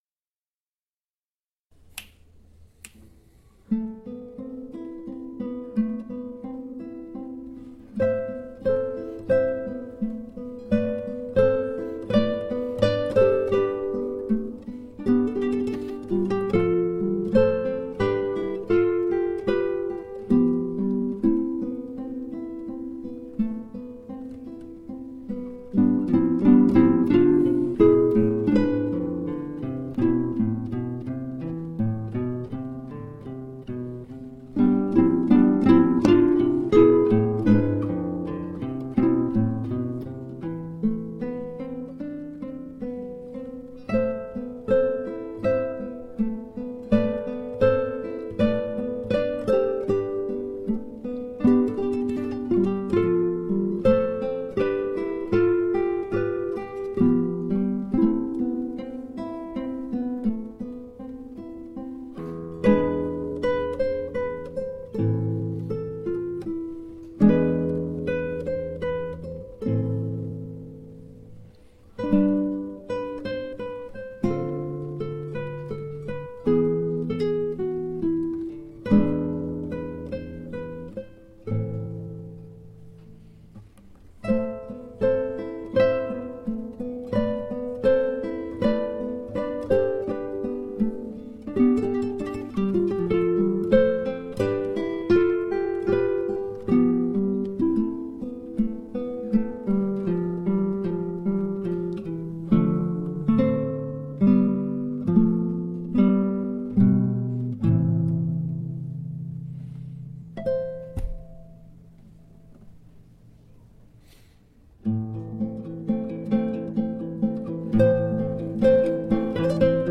l'enregistrement vitesse normale
(sans les reprises)